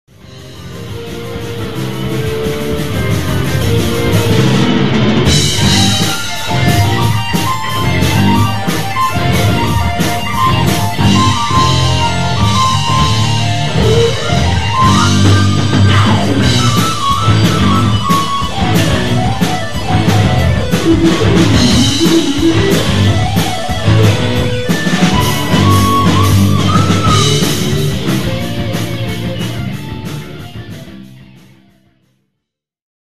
Keyboard Solo
（東京大学駒場祭2002　ＦＧＡライブより）
keyboardsolo.mp3